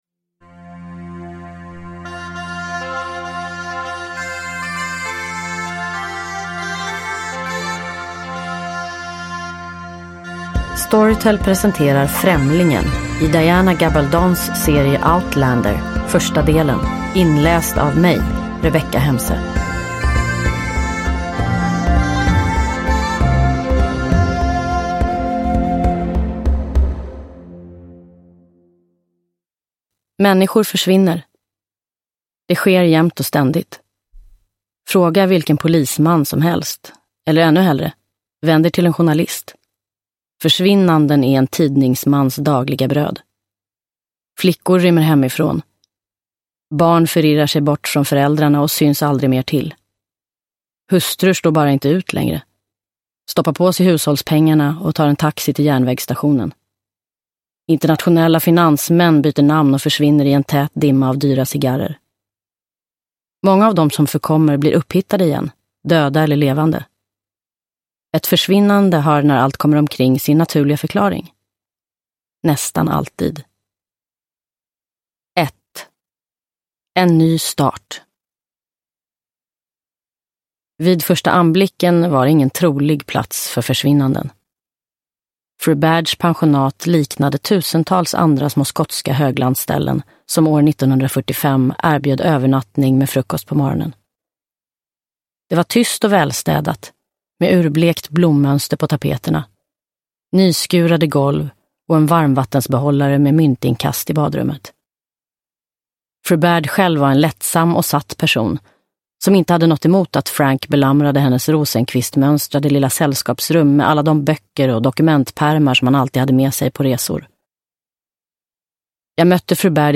Främlingen - del 1 – Ljudbok – Laddas ner
Uppläsare: Rebecka Hemse